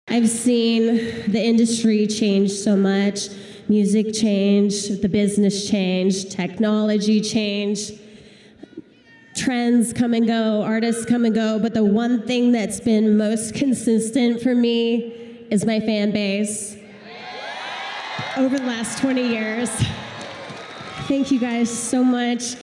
Pop punk princess Avril Lavigne of Napanee has been named the winner of the Fan Choice Award at the Juno ceremony last night in Edmonton.